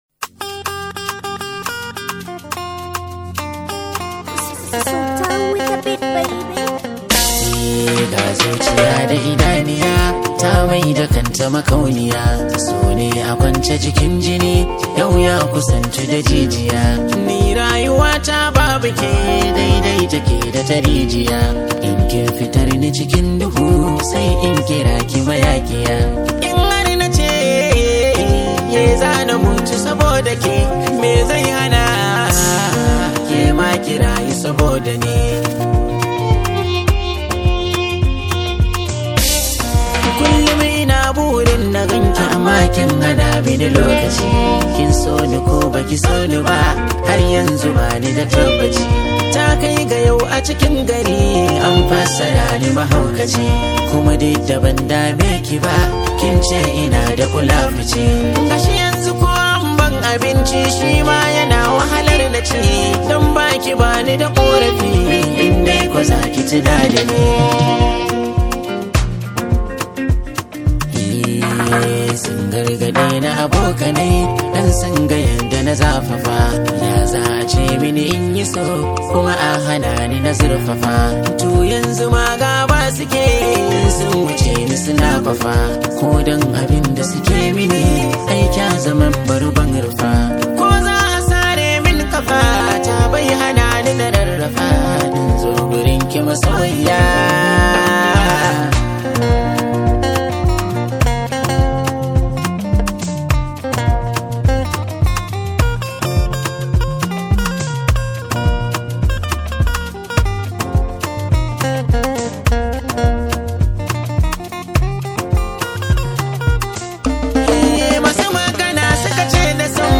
Hausa Songs
sabuwar wakar soyayya mai ratsa zuciya